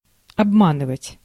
Ääntäminen
France (Paris): IPA: [a.vwaʁ]